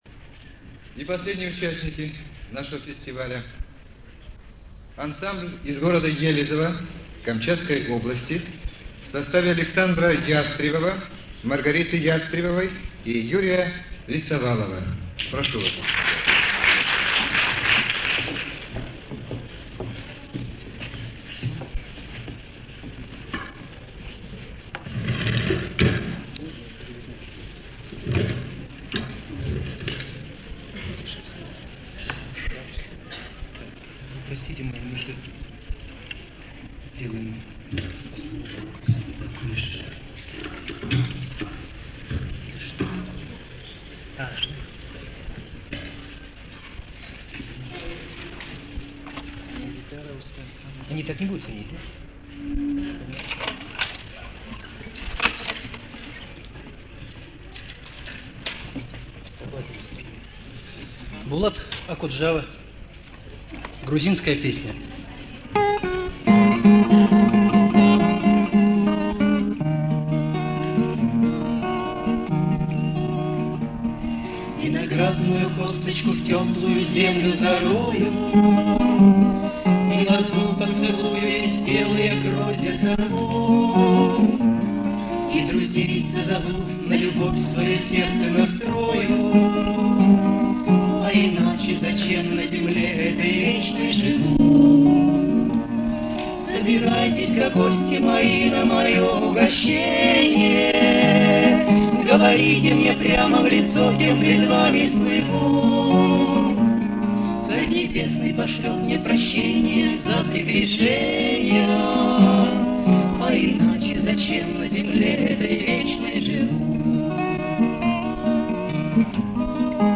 Фестиваль "Приморские струны - 82".